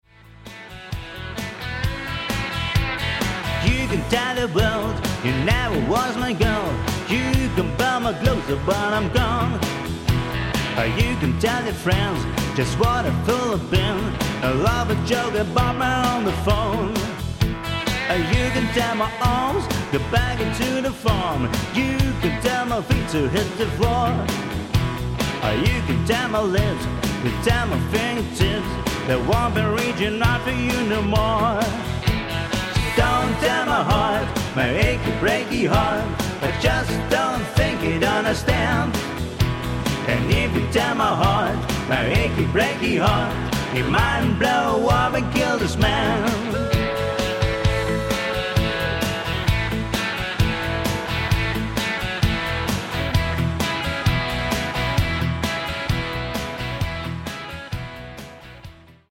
Duo